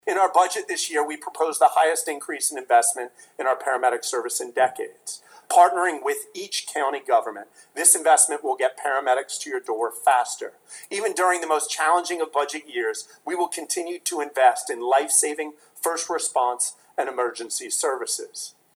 Governor Matt Meyer in his State of the State address this afternoon outlined urgent action to address Delaware’s healthcare workforce crisis with a focus on reducing ER wait times and attracting more doctors and nurses, especially in Kent and Sussex Counties.